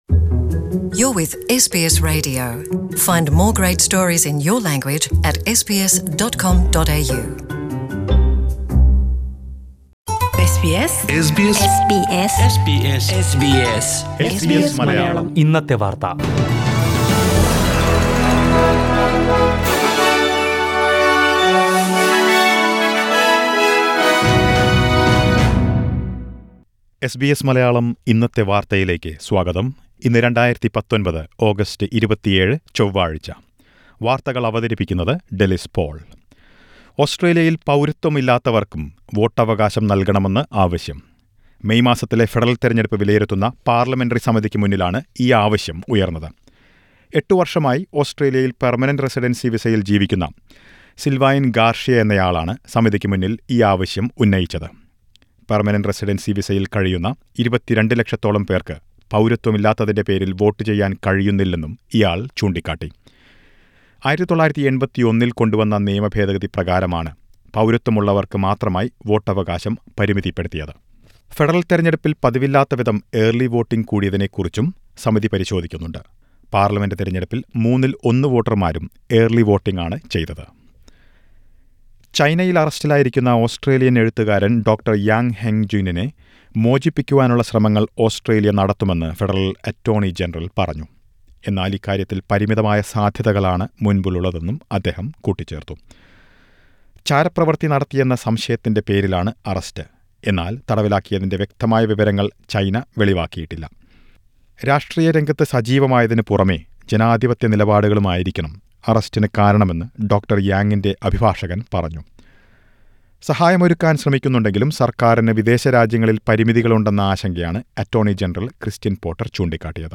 SBS Malayalam Today's News: August 27 , 2019